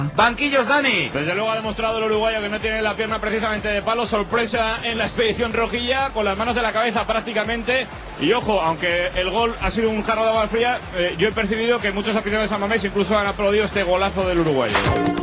Connexió amb el micròfon sense fils del partit Athletic Club - Atlético de Madrid, després que Diego Forlán fes el primer gol per al'Atlético de Madrid
Esportiu